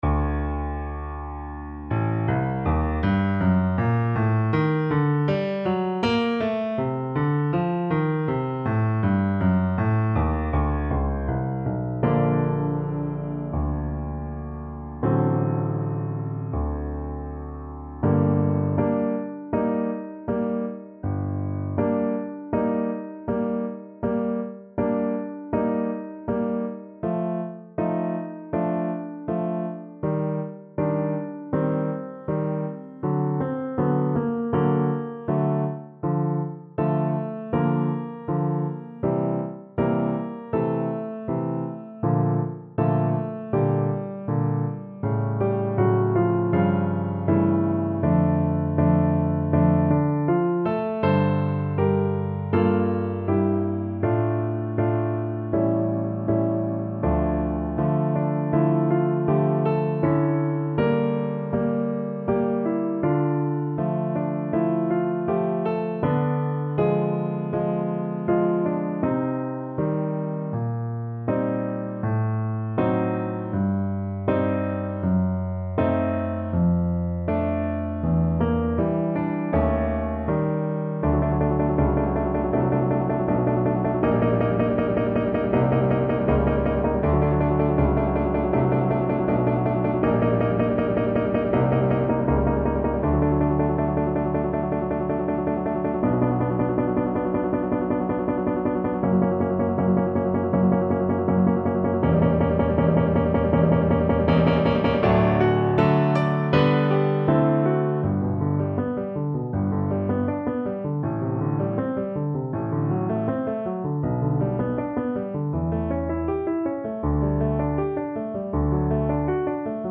Play (or use space bar on your keyboard) Pause Music Playalong - Piano Accompaniment Playalong Band Accompaniment not yet available transpose reset tempo print settings full screen
G minor (Sounding Pitch) (View more G minor Music for Flute )
~ = 100 Molto moderato =80
Classical (View more Classical Flute Music)